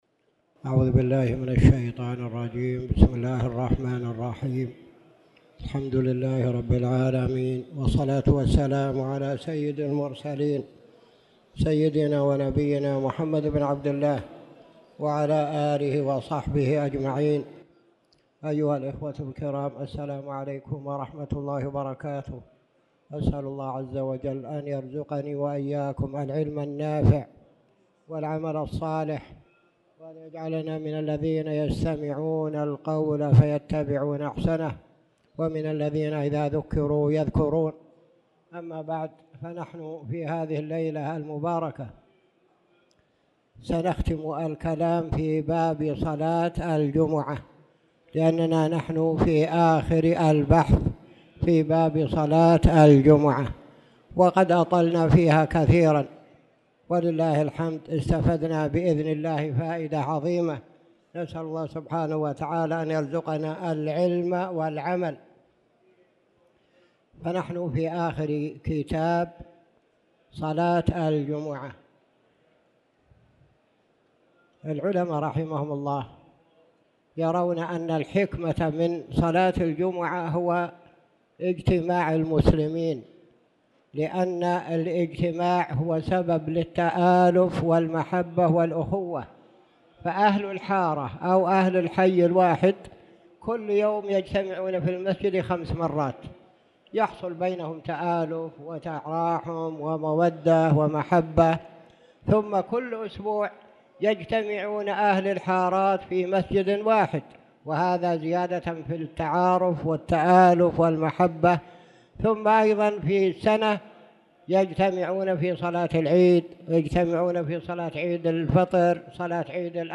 تاريخ النشر ١٤ شعبان ١٤٣٧ هـ المكان: المسجد الحرام الشيخ